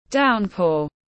Downpour /ˈdaʊn.pɔːr/